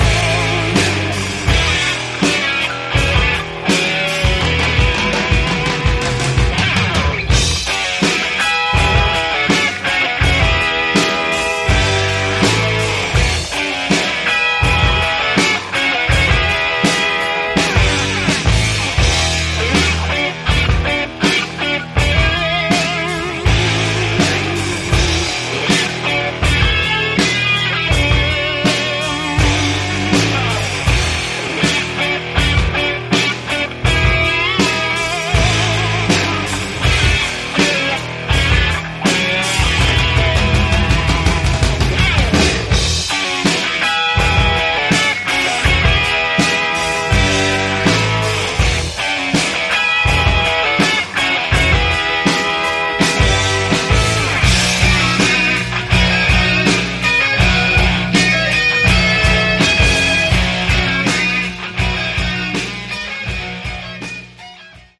Category: AOR/Hard Rock